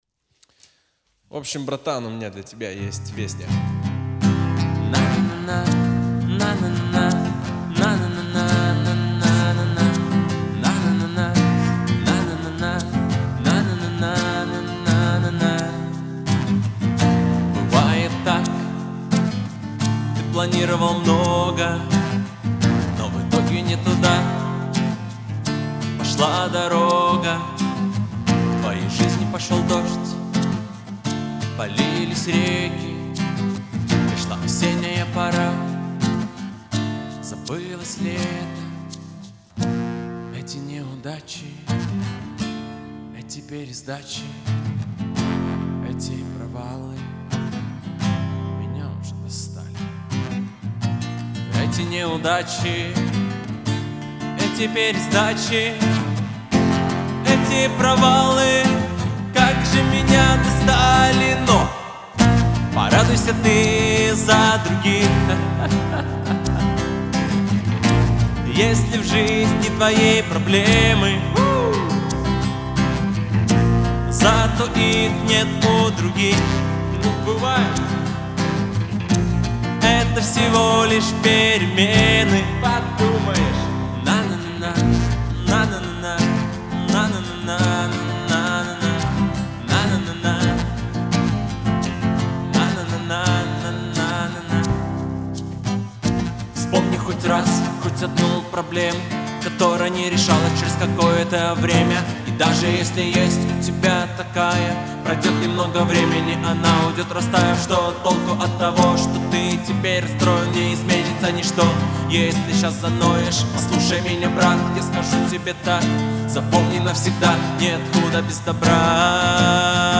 песня
231 просмотр 88 прослушиваний 8 скачиваний BPM: 85